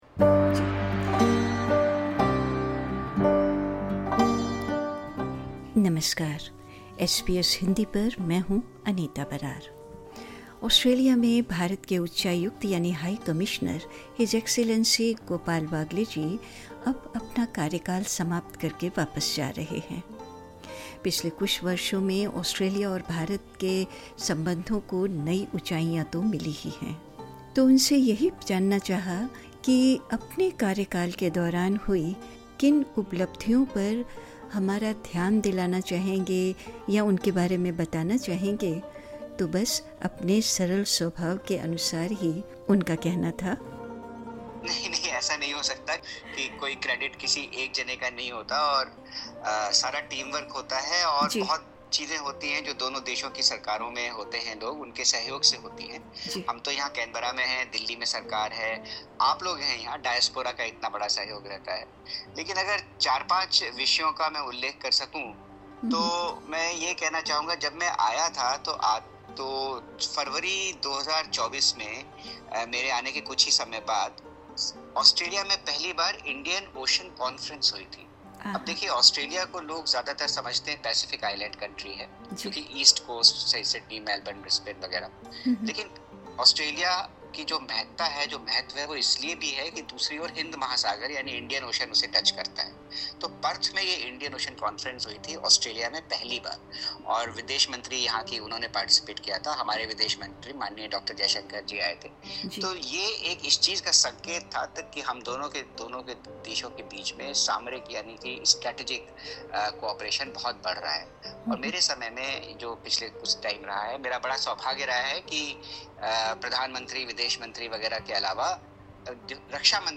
Speaking to SBS Hindi, outgoing Indian High Commissioner to Australia Gopal Baglay reflected on the strategic partnerships developed across multiple sectors, as well as the surprising lessons he learned about Australian coffee culture. He also expressed hope for the active negotiation of the Comprehensive Economic Cooperation Agreement (CECA) to expand on ECTA, focusing on critical minerals, digital trade, and education.